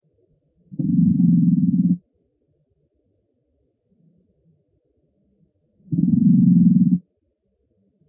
TETRIX TENUICORNIS TENUICORNIS - ������������ ������������� III ����